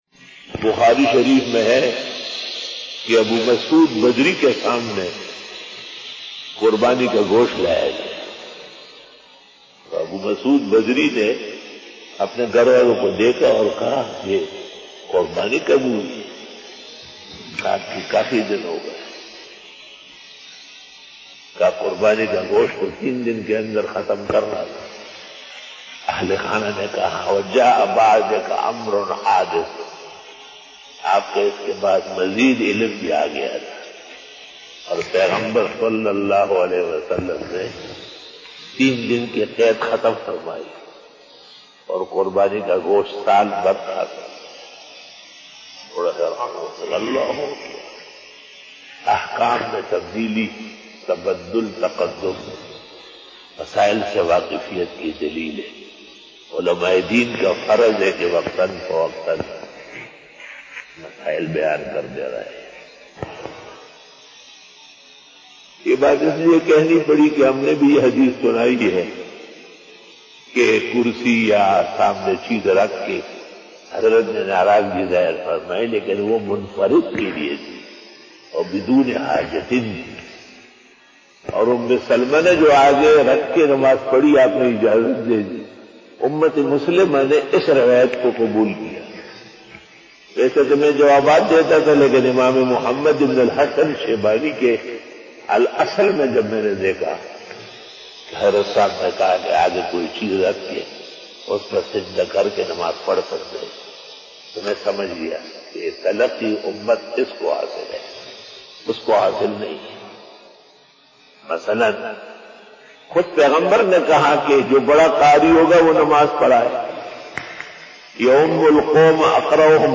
After Fajar bayan 08 October 2020 (20 Safar ul Muzaffar 1442HJ) Thursday
بیان بعد نماز فجر